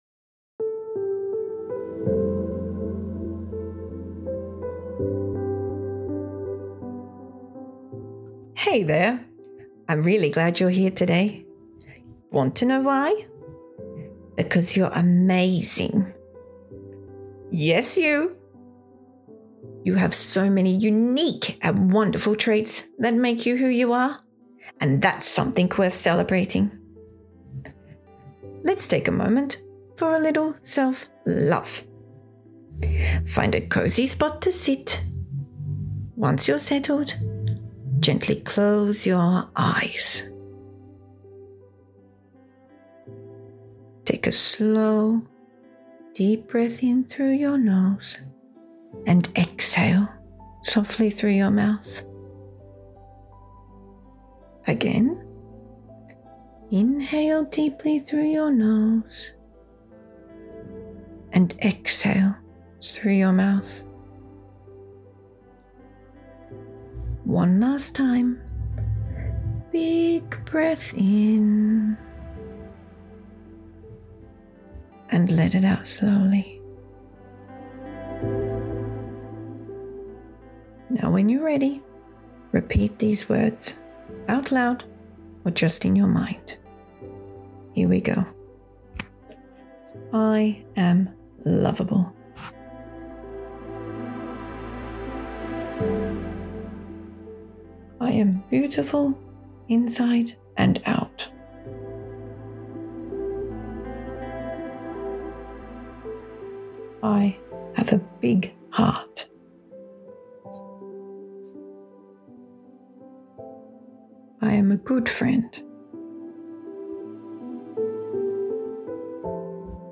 The current audio is a gentle meditation with self-love positive affirmations, accompanied with nicely designed posters.
Meditation-with-self-love-affirmations-English.mp3